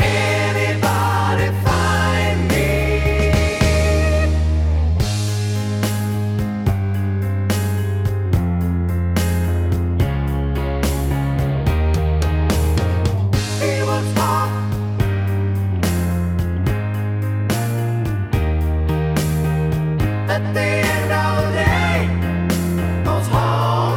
With Claps Rock 5:00 Buy £1.50